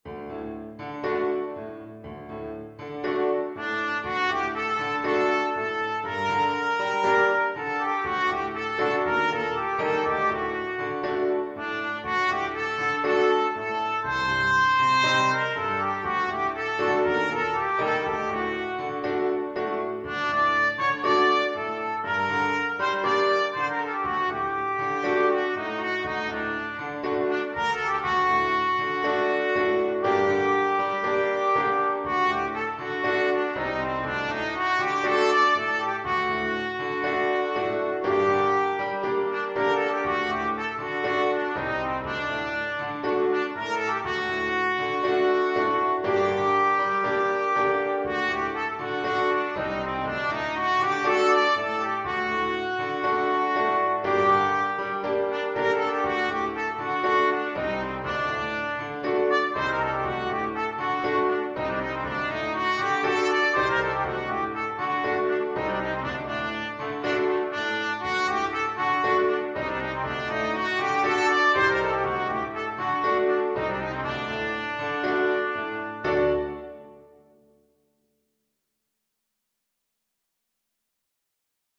Traditional Trad. Araber Tanz (Klezmer) Trumpet version
Trumpet
4/4 (View more 4/4 Music)
G minor (Sounding Pitch) A minor (Trumpet in Bb) (View more G minor Music for Trumpet )
Allegro moderato =120 (View more music marked Allegro)
A4-D6
Traditional (View more Traditional Trumpet Music)
world (View more world Trumpet Music)